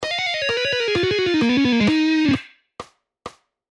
Legato Guitar Exercise
Lessons-Guitar-Mark-Tremonti-Legato-Exercises-4.mp3